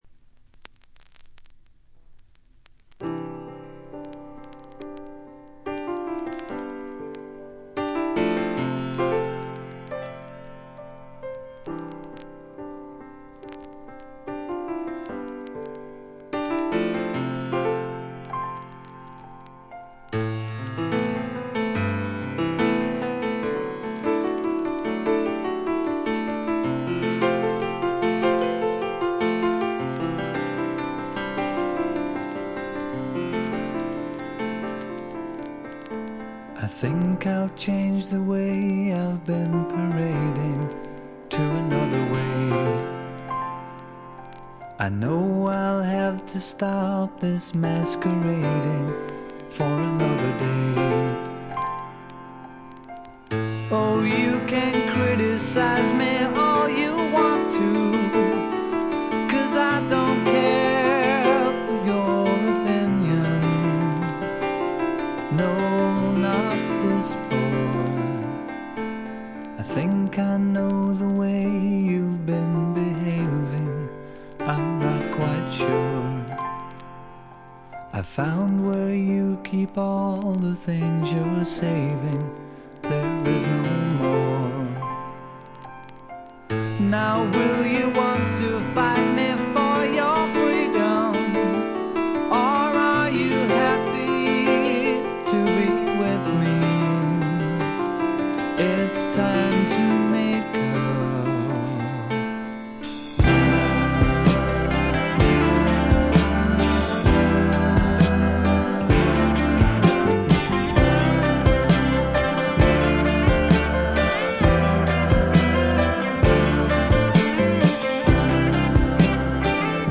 B面は静音部でチリプチ。
味わい深い美メロの良曲が連続する快作。
試聴曲は現品からの取り込み音源です。